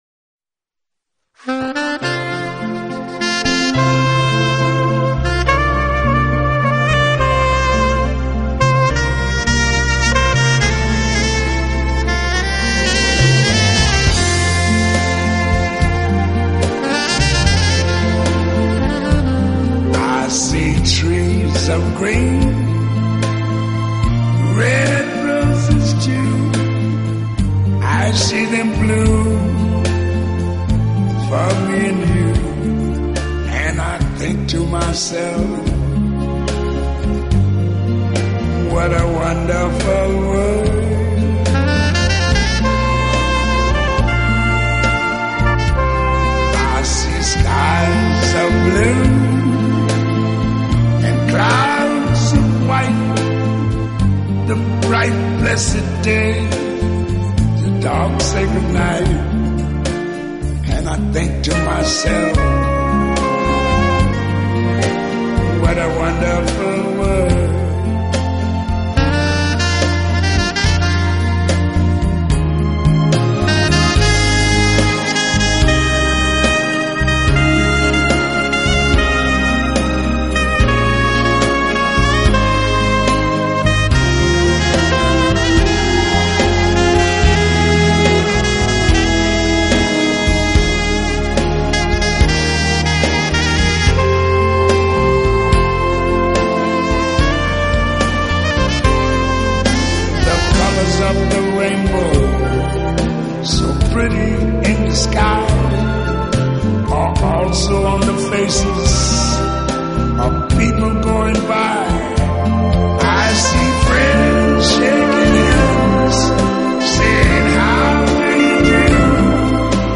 音乐风格：Smooth Jazz